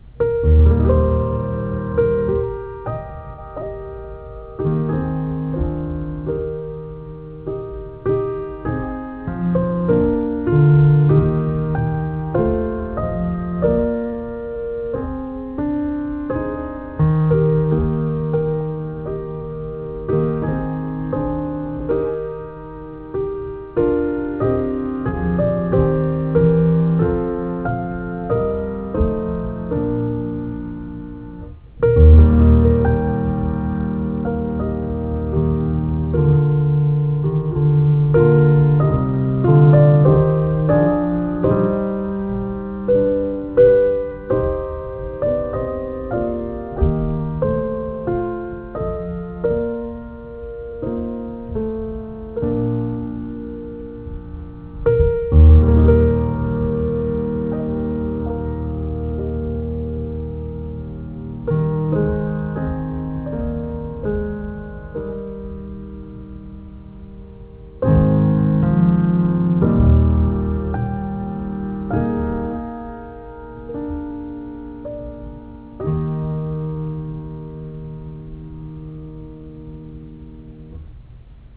Arranged and performed by Victor Borge in a beautiful and melancholy way.